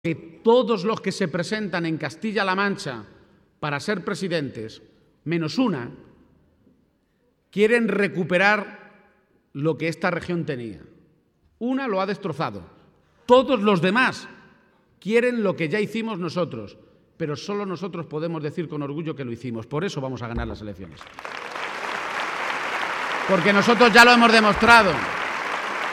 García-Page se pronunciaba de esta manera esta tarde, en Toledo, en un acto político que ha congregado a más de 1.500 personas en el Palacio de Congresos de la capital regional y en el que ha compartido escenario con el secretario general del PSOE, Pedro Sánchez, y la candidata socialista a suceder al propio García-Page en la alcaldía de Toledo, Mlagros Tolón.